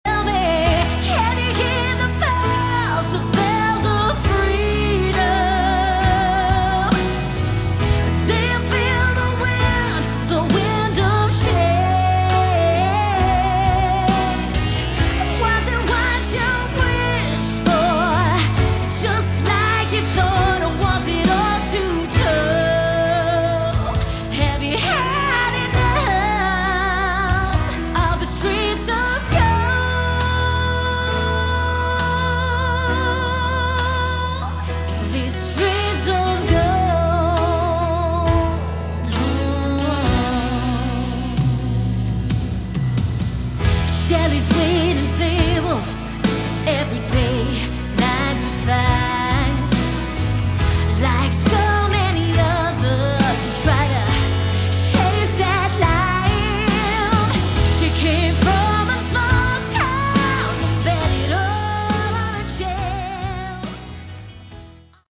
Category: Melodic Rock
vocals